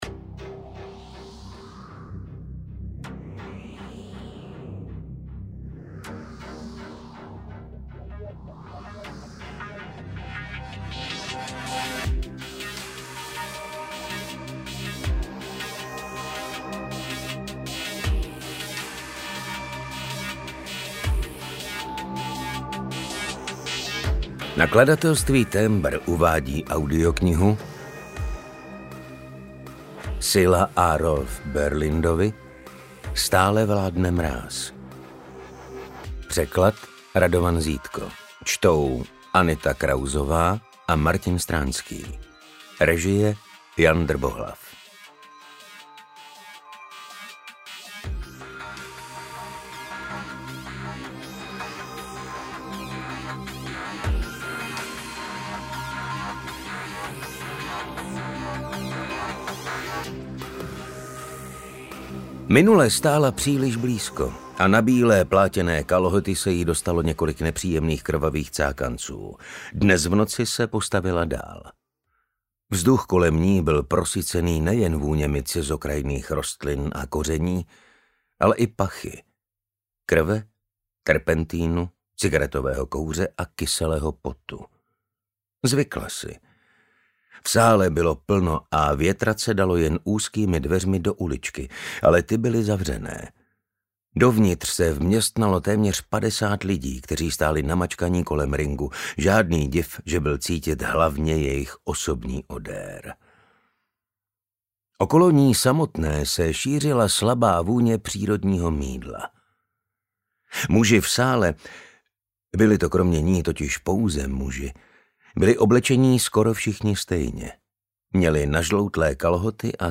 UKÁZKA Z KNIHY
Čte: Martin Stránský a Anita Krausová
audiokniha_stale_vladne-mraz_ukazka.mp3